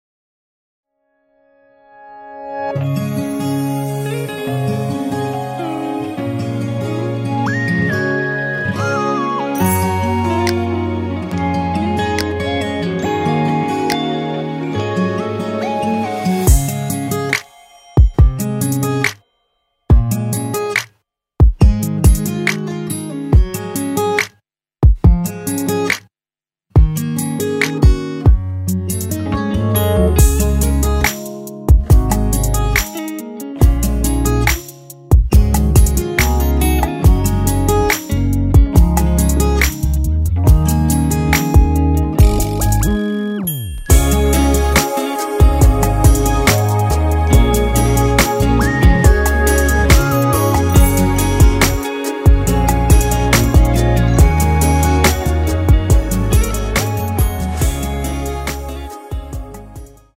歌曲调式：升C大调